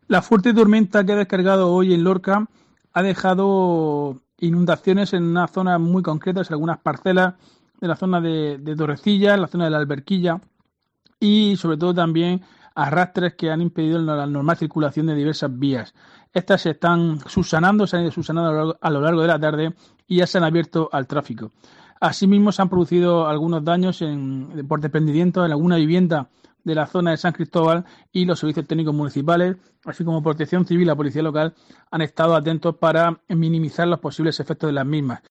Diego José Mateos alcalde de Lorca, sobre lluvias